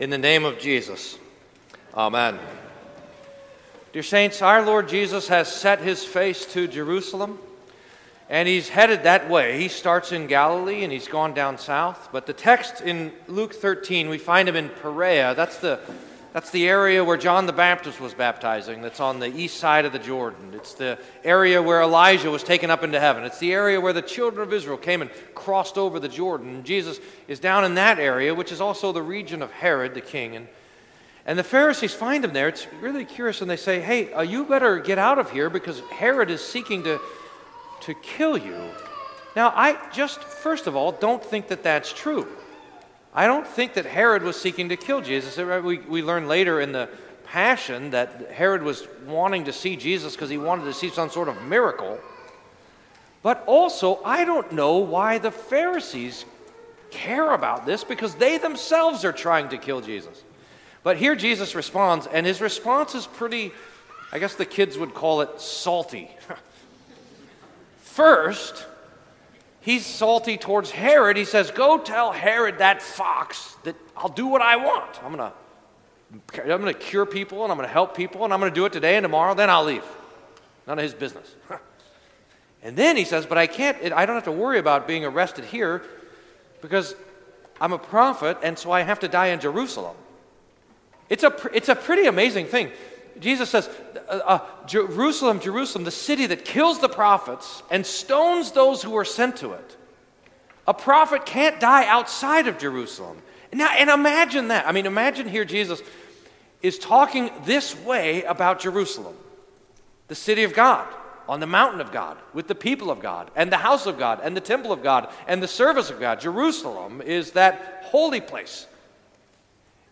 Sermon for Second Sunday in Lent